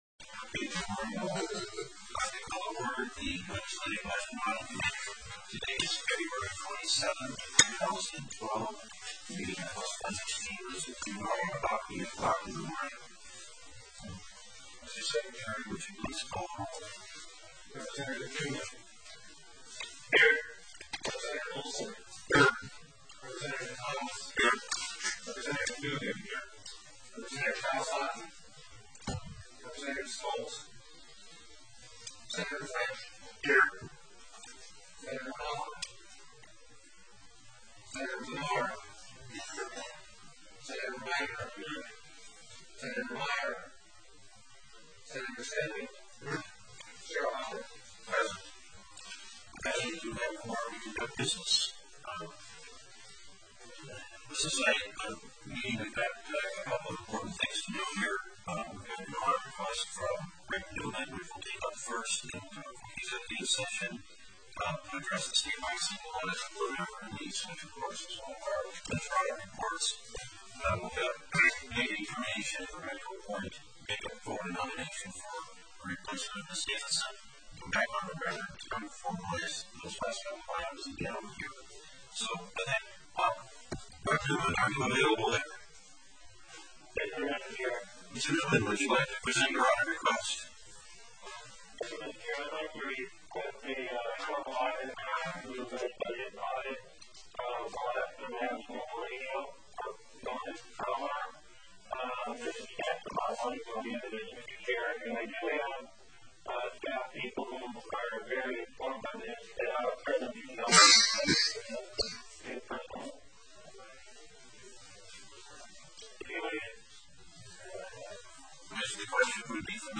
Representative Mike Hawker, Chair
Representative Mark Neuman (via teleconference)